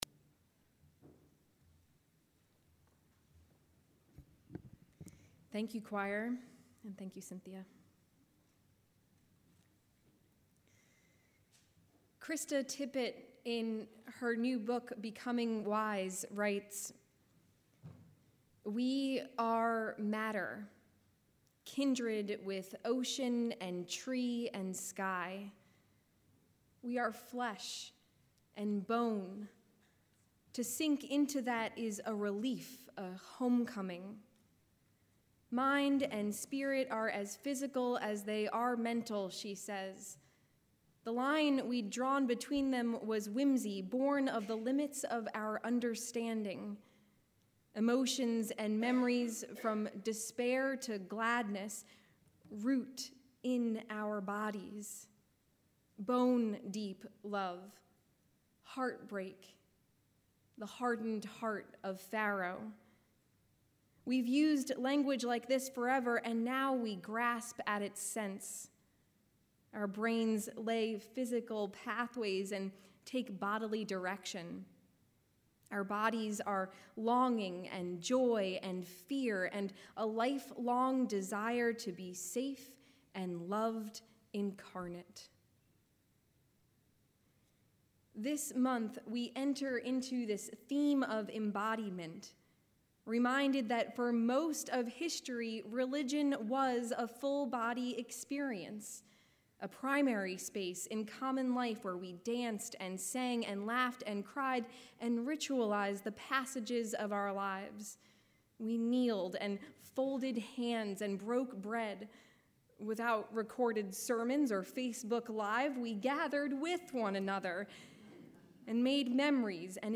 A Service for Embodiment